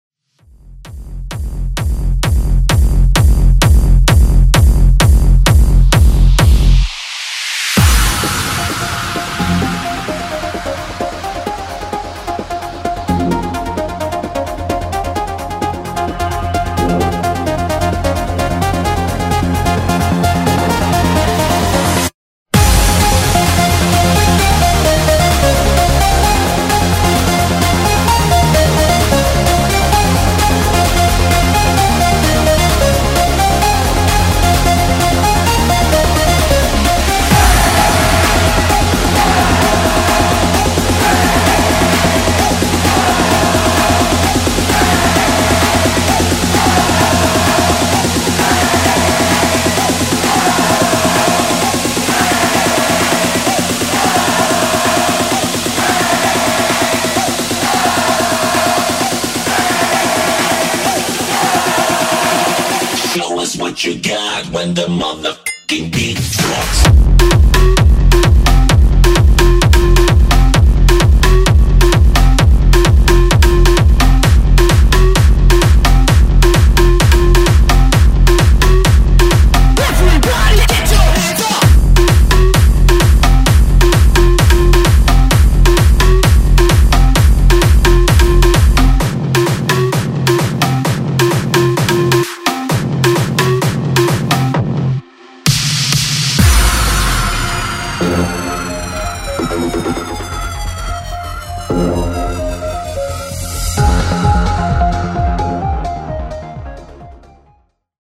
BPM: 130 Time